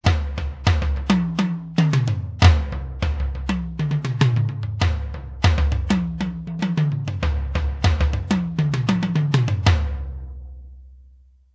I file MIDI sono le take originali così come sono state registrate con il guitar synth: non c'è stato nessun editing "a posteriori".
Drums (MIDI) (
GuitarSynthDrumDemo.mp3